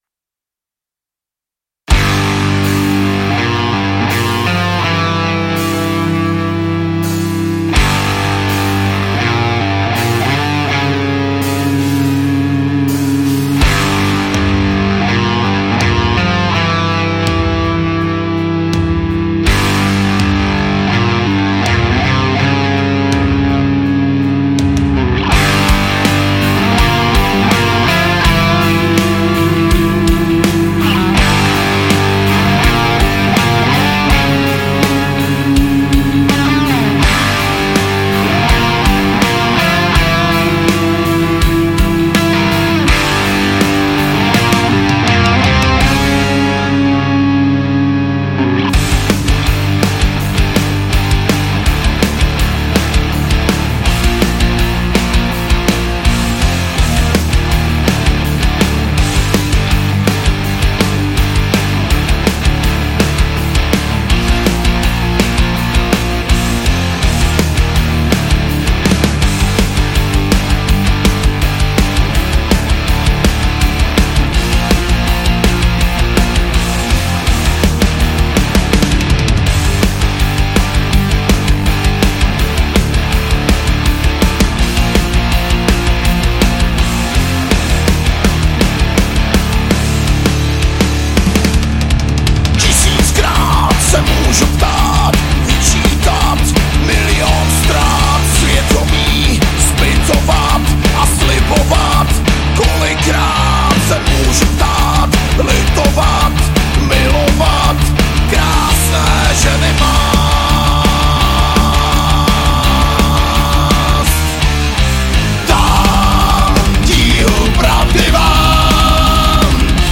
Žánr: Metal/HC
Bicí se tvořily v karvinském studiu Digaz.
basa, doprovodný zpěv
kytara